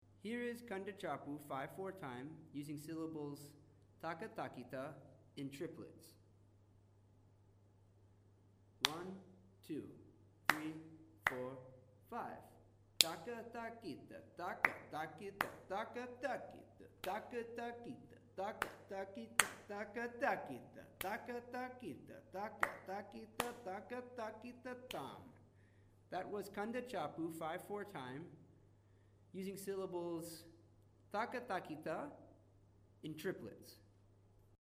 It is shown through a series of claps: Clap on beat 1; Clap on beat 3; Clap on beat 4.
Each clip shows Kanda Chapu thalum while reciting Ta Ka Ta Ki Ta in different subdivisions.
kandachapu-8thnotetriplets.mp3